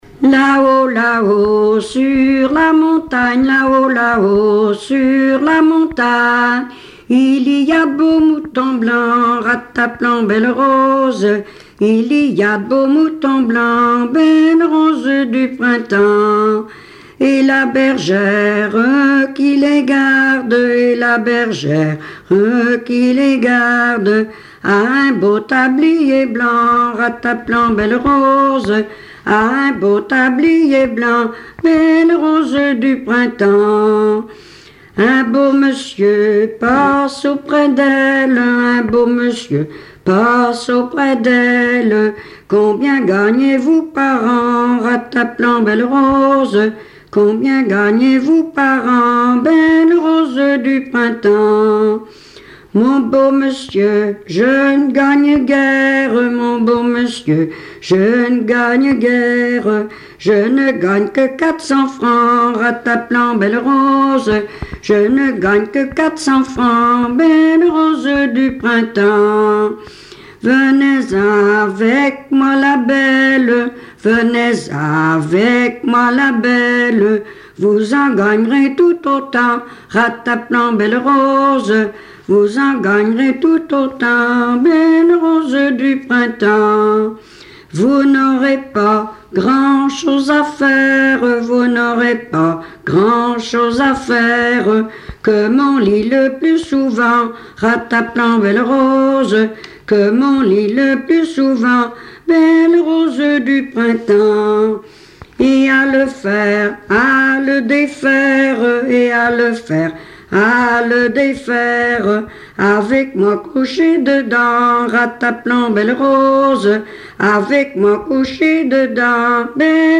Mémoires et Patrimoines vivants - RaddO est une base de données d'archives iconographiques et sonores.
Genre laisse
Chansons traditionnelles et populaires
Pièce musicale inédite